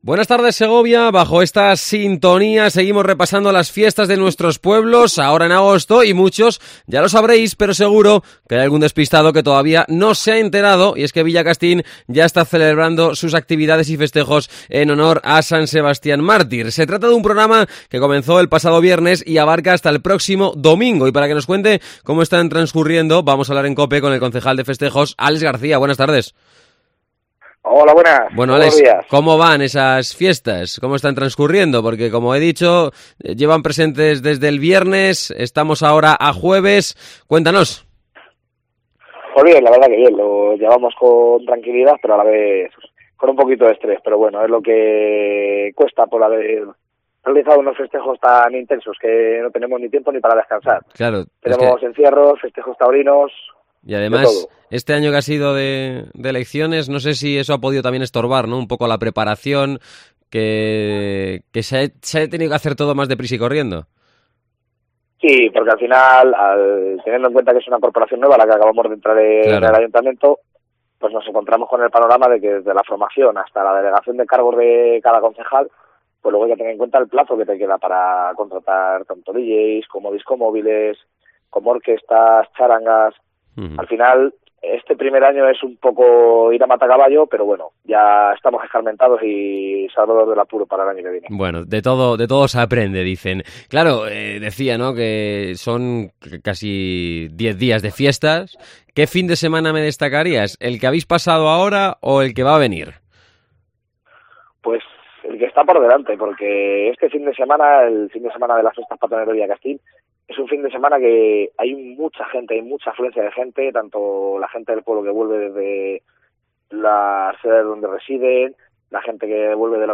Entrevista a Alex García, Concejal de Festejos de Villacastín.